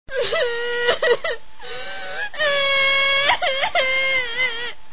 Crying